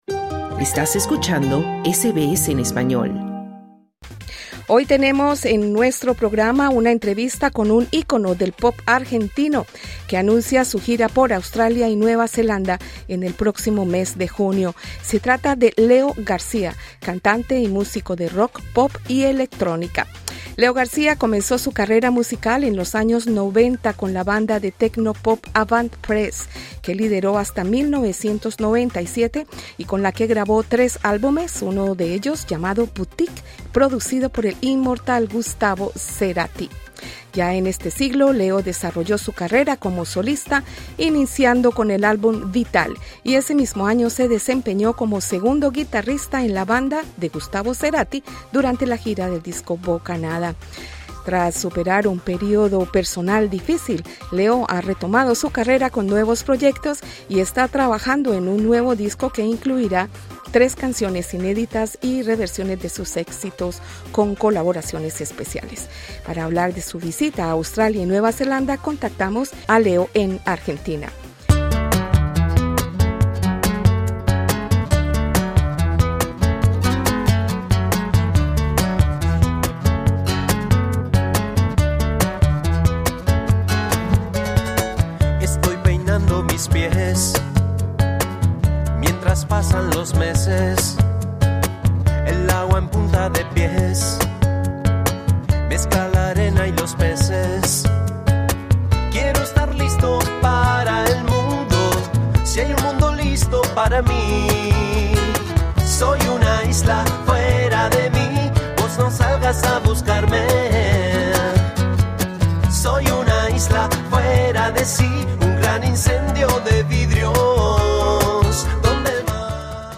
El músico argentino Leo García anuncia su gira por Australia y Nueva Zelanda. En entrevista con Australia en español, García reflexiona sobre su recorrido artístico, su visión espiritual del presente y la identidad que ha construido como trovador contemporáneo.